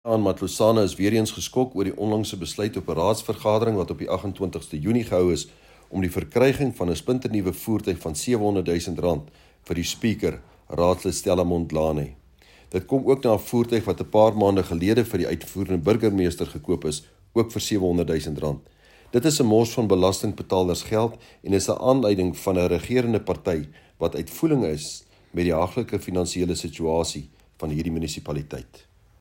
Issued by Cllr Johannes Le Grange – DA Caucus Leader: City of Matlosana
Afrikaans by Cllr Johannes Le Grange